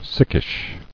[sick·ish]